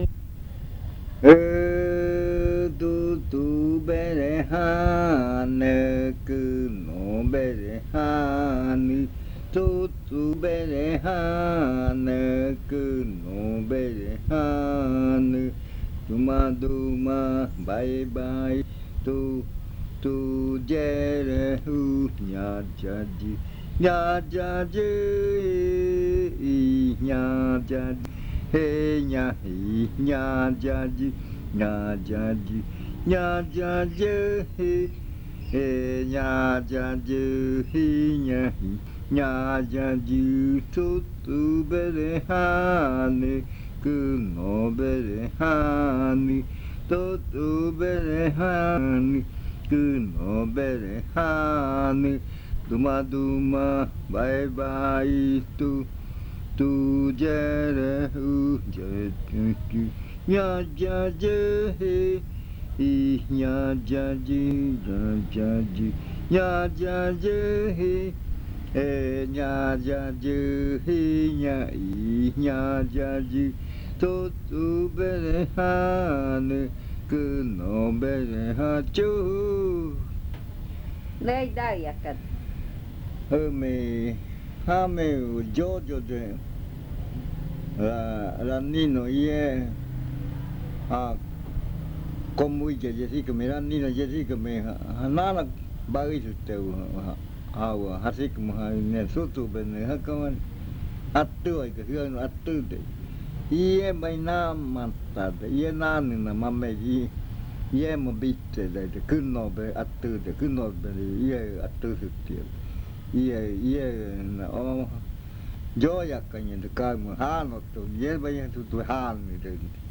Leticia, Amazonas
02:00 AM. Plantío de frutas silvestres zuzubere. (Casete original
Este canto hace parte de la colección de cantos del ritual Yuakɨ Murui-Muina (ritual de frutas) del pueblo Murui